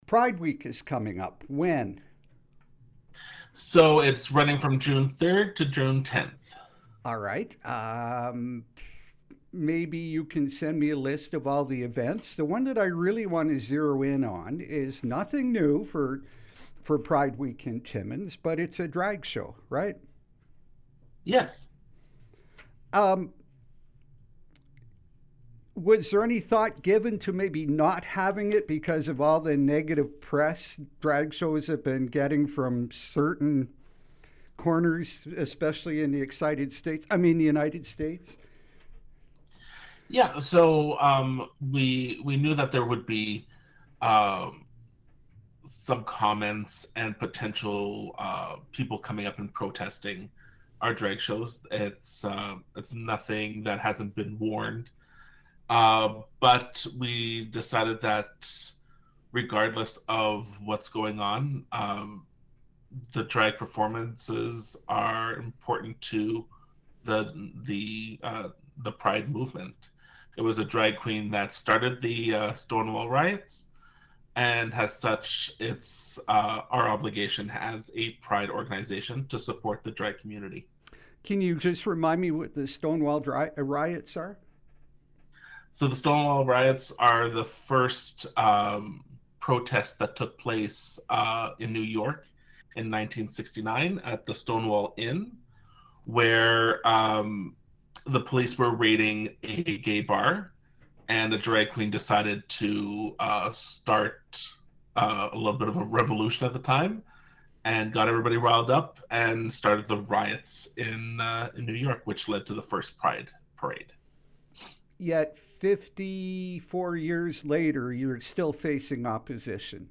For more on Pride, the history behind it and drag shows, our interview